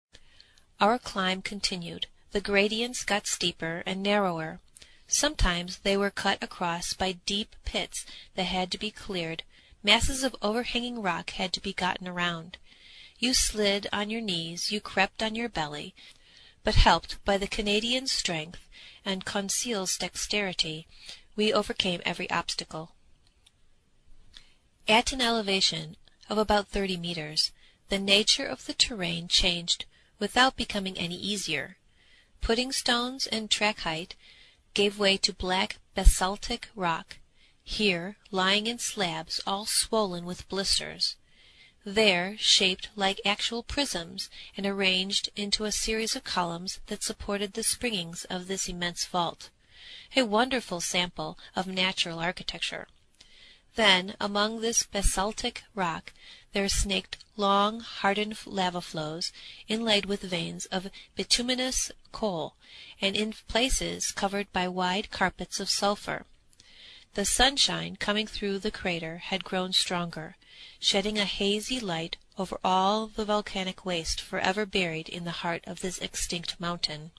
英语听书《海底两万里》第402期 第25章 地中海四十八小时(28) 听力文件下载—在线英语听力室
在线英语听力室英语听书《海底两万里》第402期 第25章 地中海四十八小时(28)的听力文件下载,《海底两万里》中英双语有声读物附MP3下载